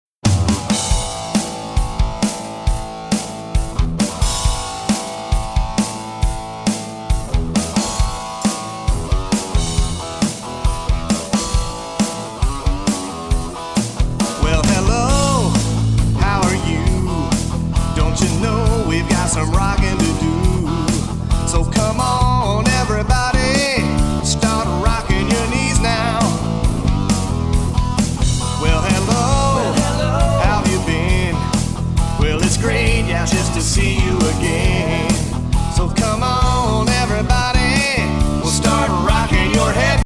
Filled from beginning to end with rockin’ movement tunes